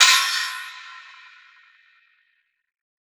Crash [Screech].wav